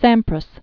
(sămprəs), Peter Known as "Pete."